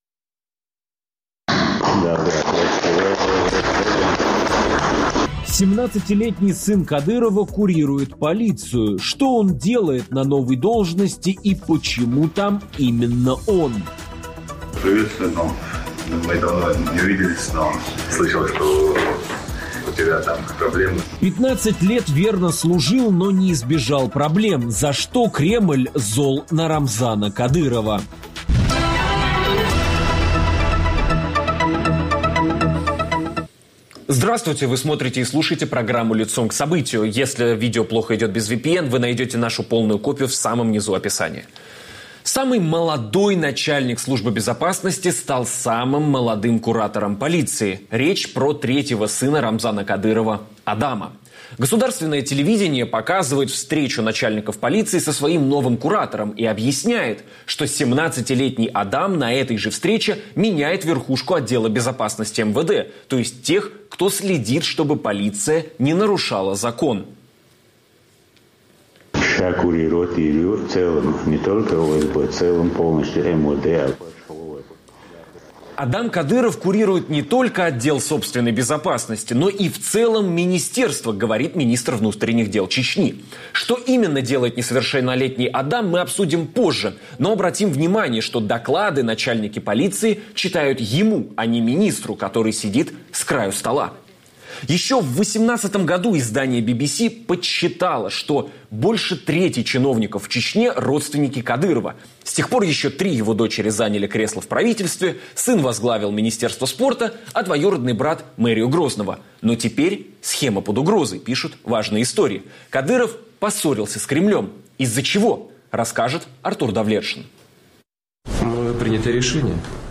обсуждаем с политиком Ахмедом Закаевым